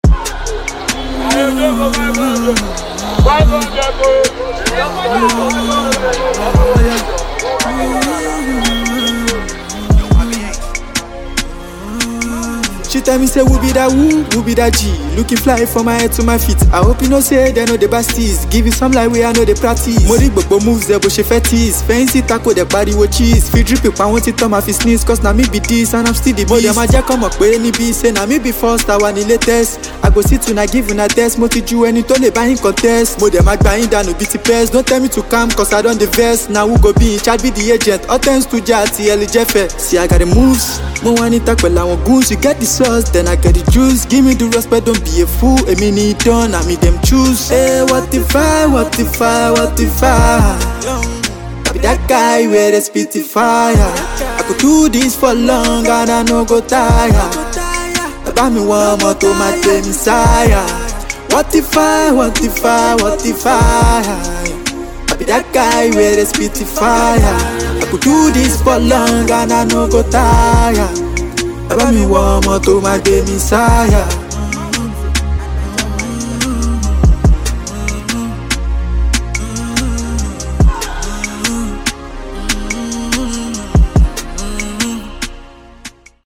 electrifying freestyle